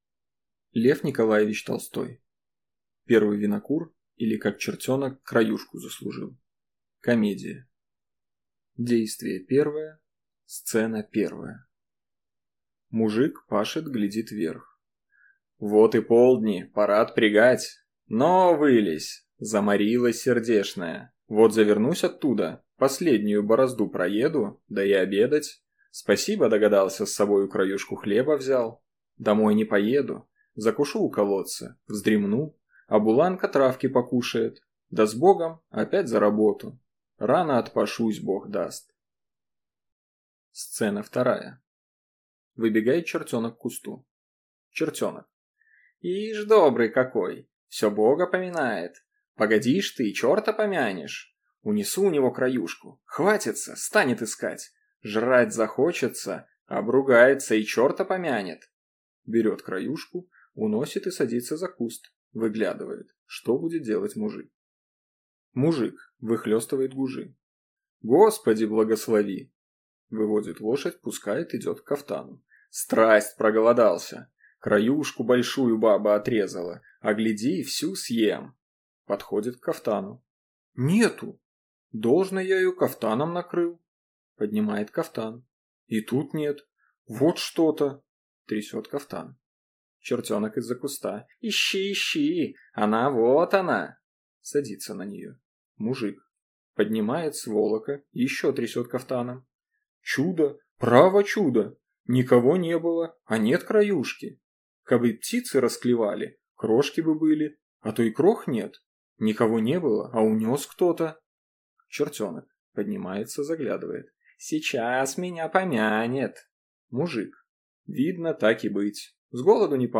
Аудиокнига Первый винокур, или Как чертенок краюшку заслужил | Библиотека аудиокниг